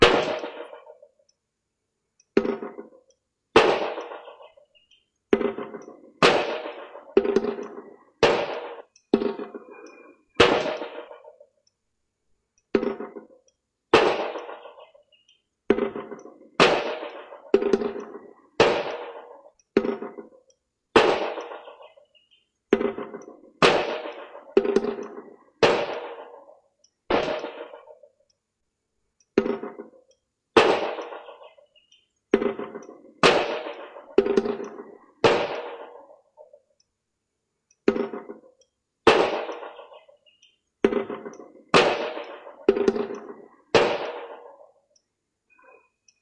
自然延迟打击乐
描述：这是在德国埃森的一个空游泳池里的打击乐。空荡荡的游泳池给了它一个自然的3时间延迟。大约在1987年用Grundig录音机录制。
Tag: 游泳池 自然 打击乐器 延迟